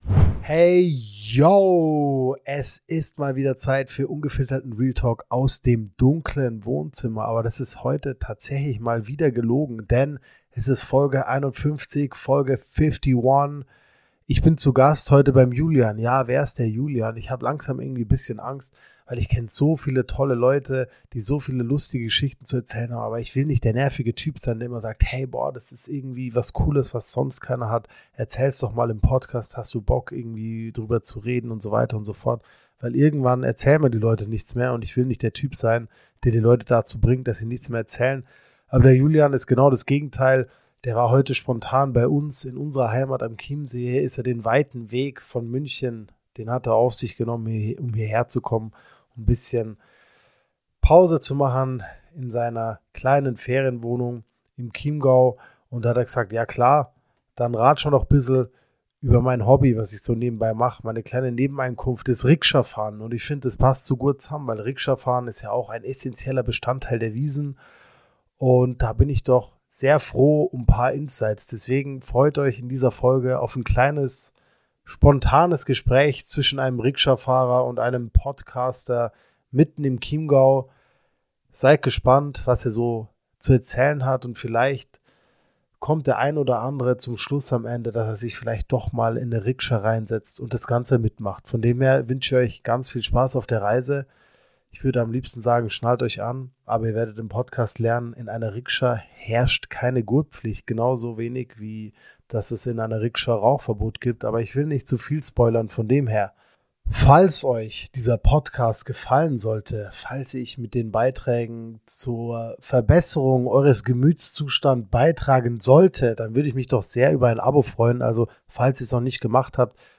Freut euch auf ein spontanes, zwangloses Gespräch in dem mal wieder aufgezeigt wird, dass die wahren Helden unserer Gesellschaft keine Stars oder Promis sind, sondern Menschen wie du und ich.